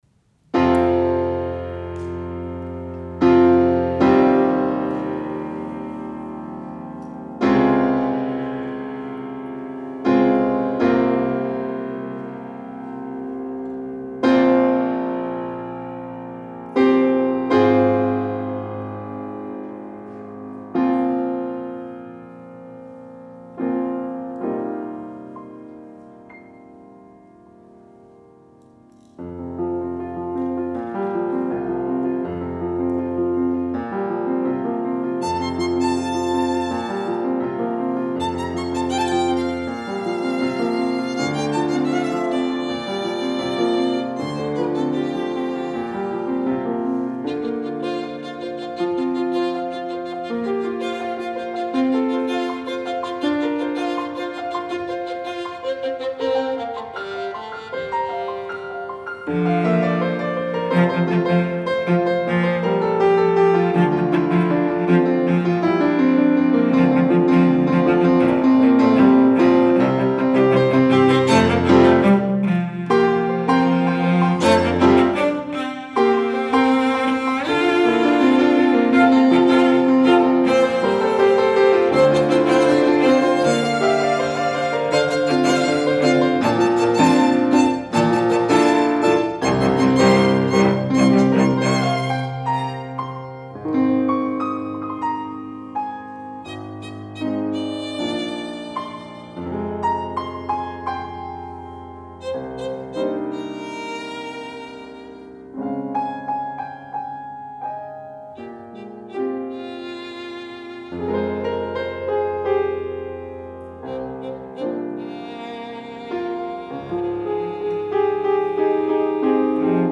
for Piano Trio (2015)